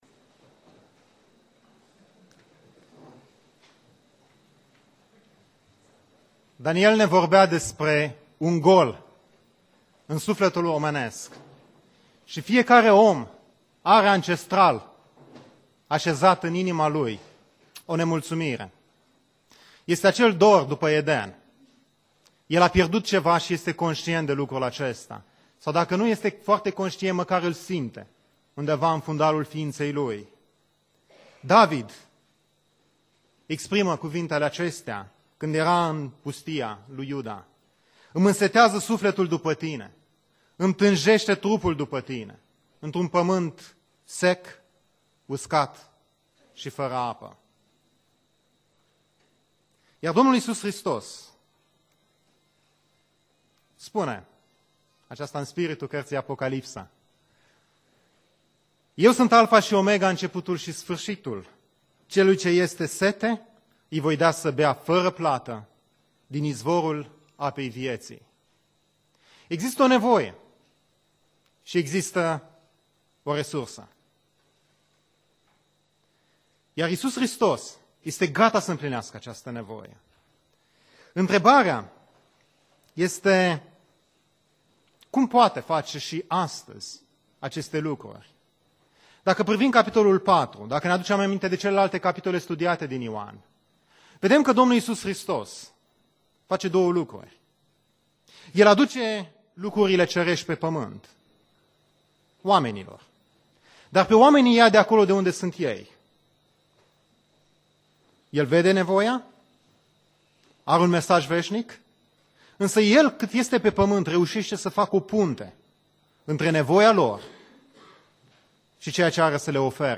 Predica Aplicatie - Ev. dupa Ioan cap 4